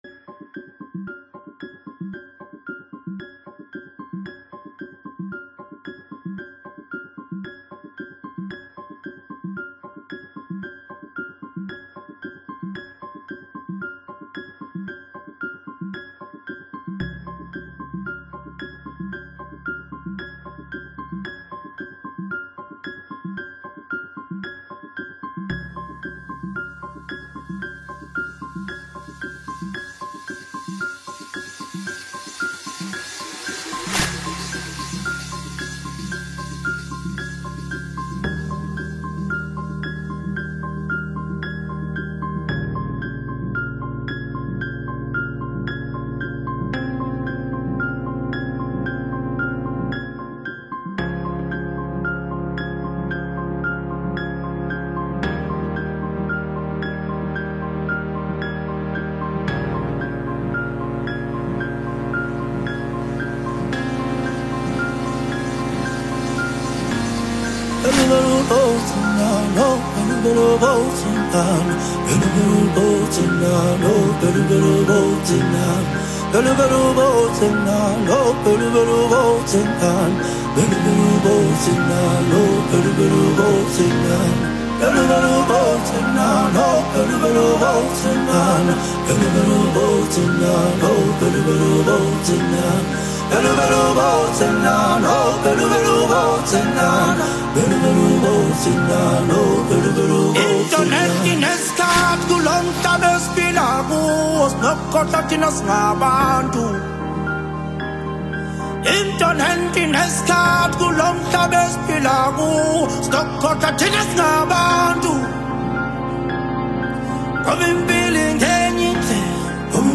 AmapianoAudioUniversal